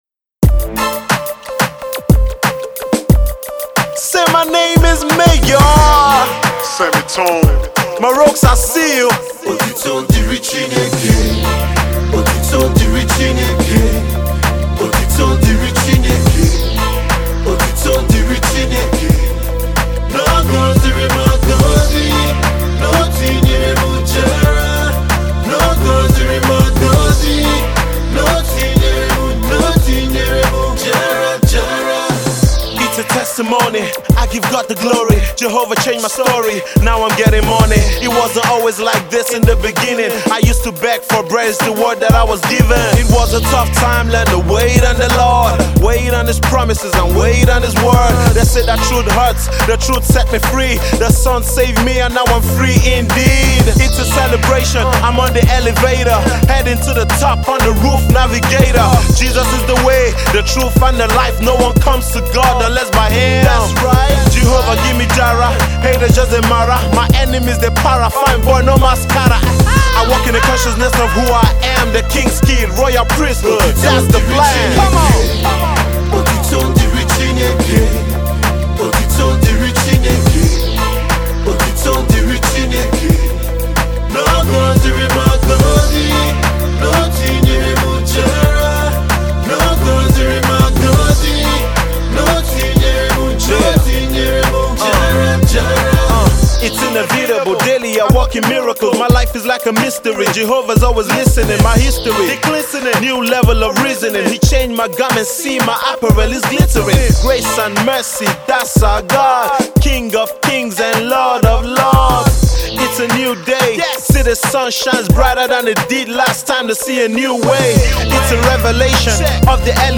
Hip-Hop
enthusiastic and energetic gospel rap icon